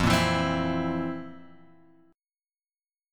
F Major 7th Suspended 4th
FM7sus4 chord {1 1 2 x 1 0} chord